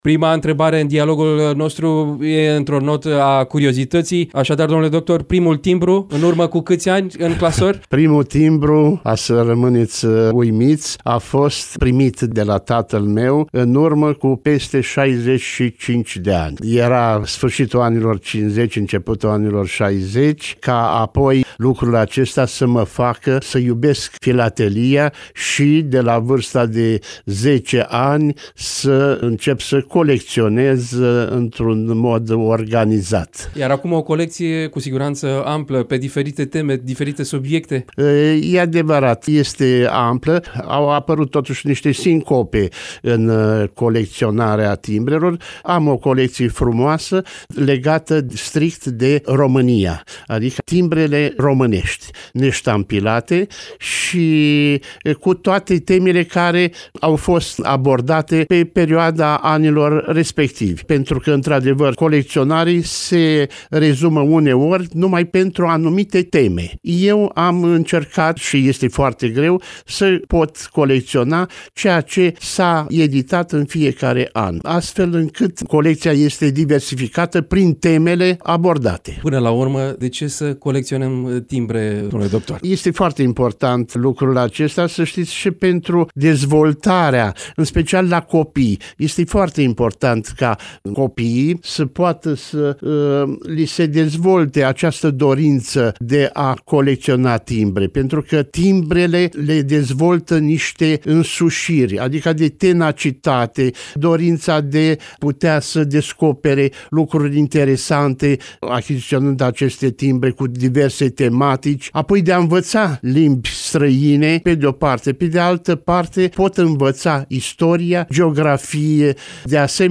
Varianta audio a dialogului: